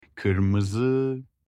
برای Kırmızı، تلفظی که باید به خاطر بسپارید کِِرمِزْ است.
red-in-turkish.mp3